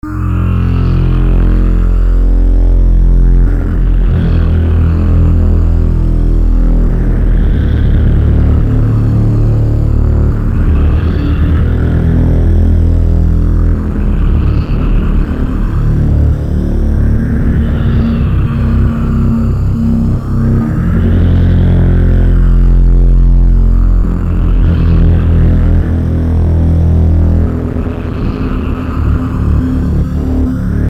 Some random old bits, raw and bloody.
I need to experiment more with noizy filters over droning synths. Gives a nice creepy effect.
Dude, srsly, you should finish working on Noizy Horror...it sounds amazing and gloomish Very Happy
noizyhorror_467.mp3